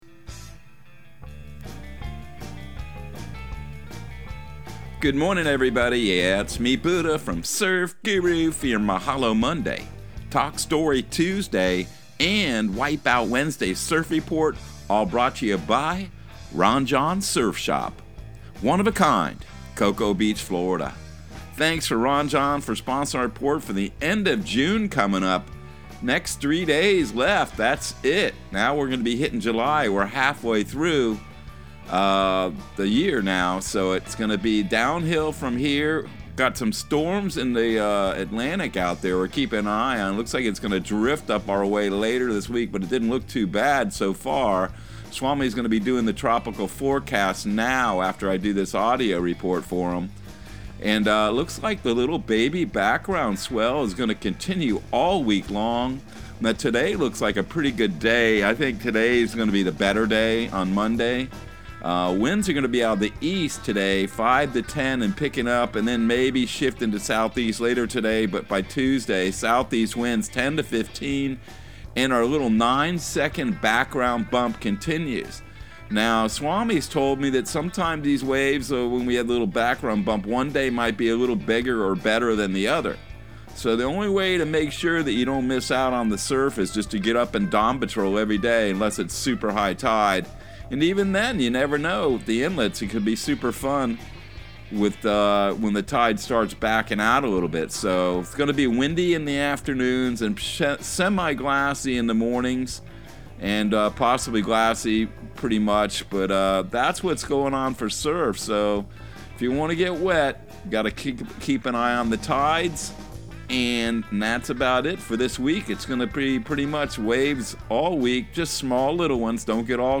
Surf Guru Surf Report and Forecast 06/27/2022 Audio surf report and surf forecast on June 27 for Central Florida and the Southeast.